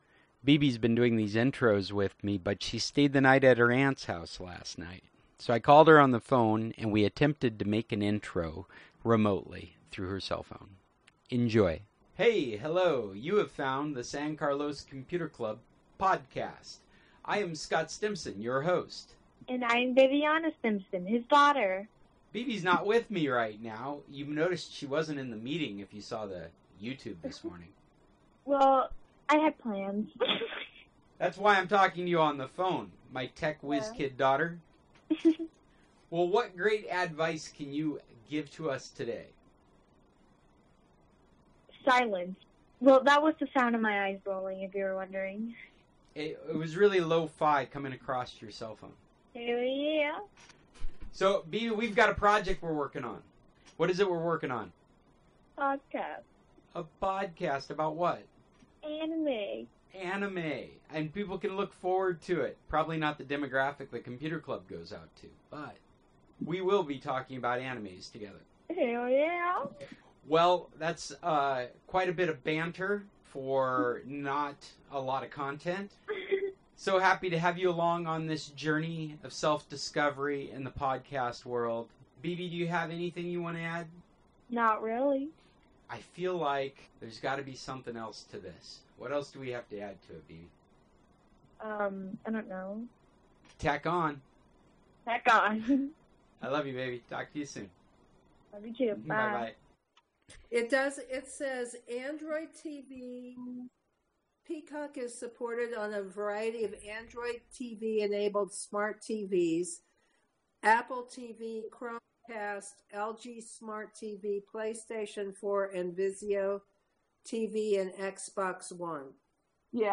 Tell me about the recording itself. I’m so happy you came to the Internet and joined us for today’s club meeting. Today many members showed up that had not been with us for a while.